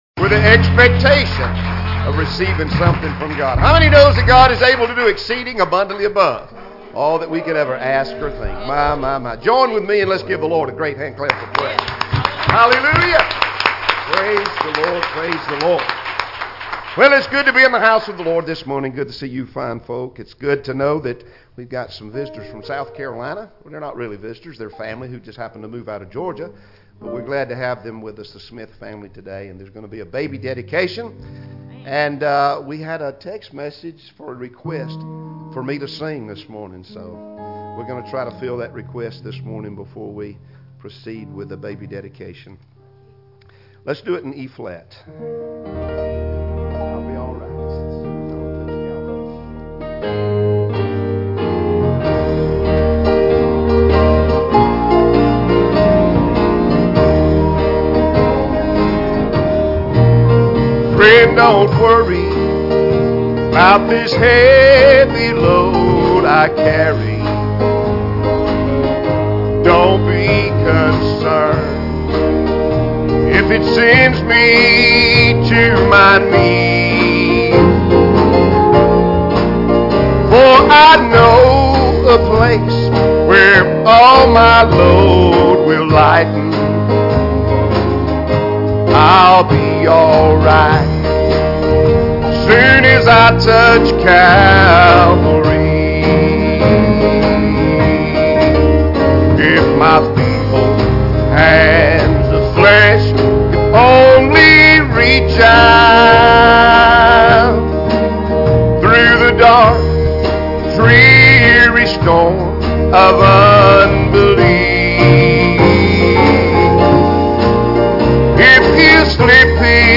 "1 John 4:14-18" Service Type: Sunday Morning Services Topics